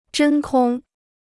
真空 (zhēn kōng): สุญญากาศ.